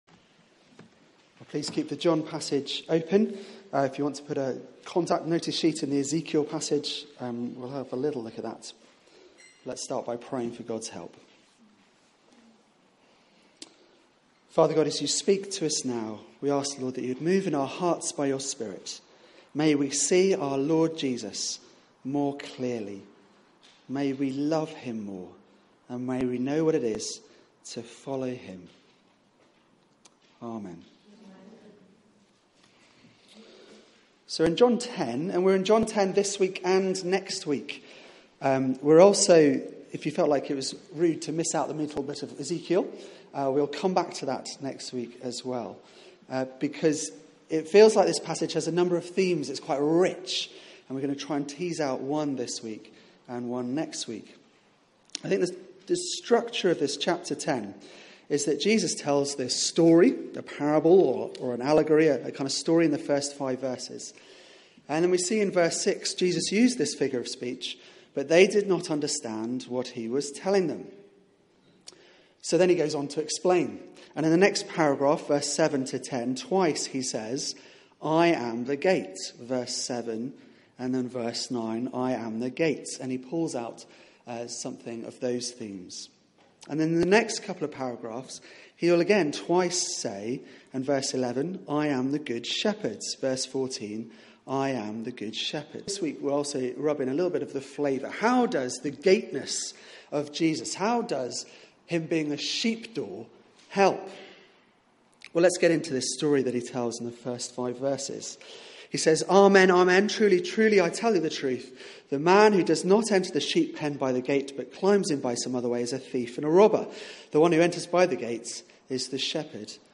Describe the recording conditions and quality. Media for 4pm Service on Sun 25th Sep 2016 16:00 Speaker